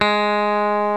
FENDER STRAT 4.wav